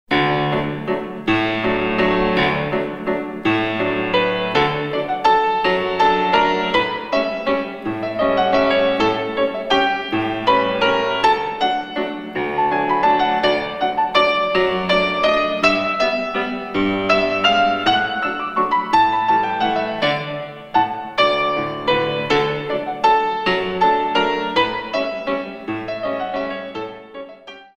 128 Counts